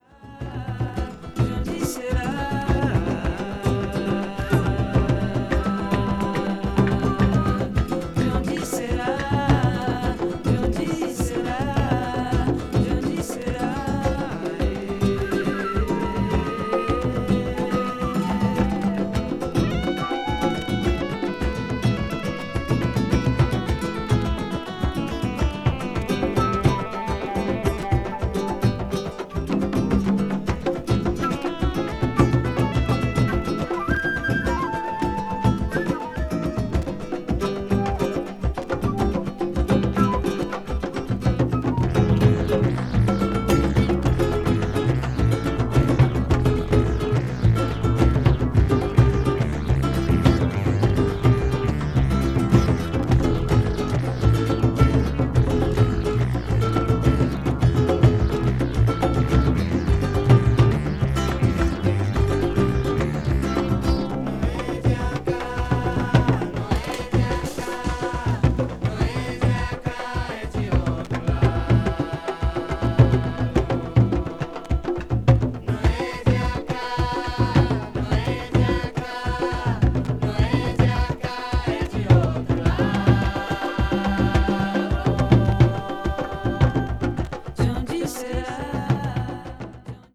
ボッサノヴァへの深い愛情を感じさせながらも、カンドンベのリズムもしっかり盛り込まれているところがウルグアイならでは。